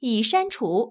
ivr-has_been_removed.wav